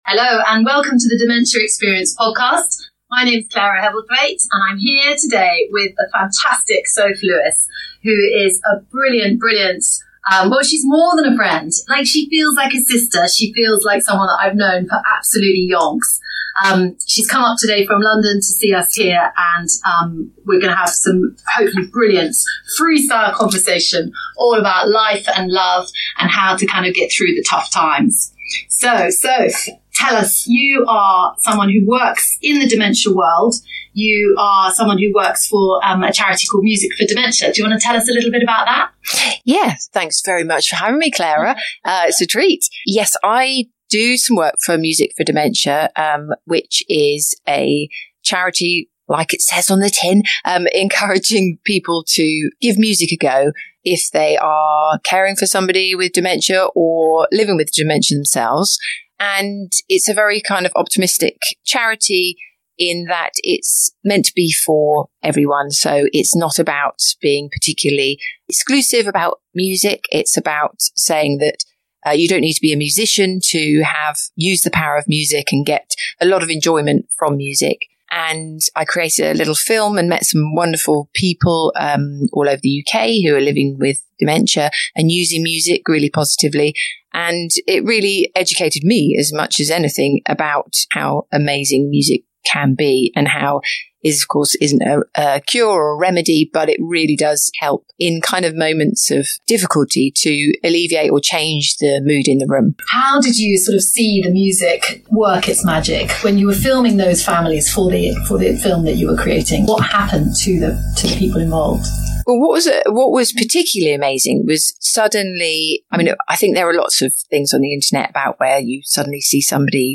Recording quality poor I'm very sorry to say but the tech dudes have done their best...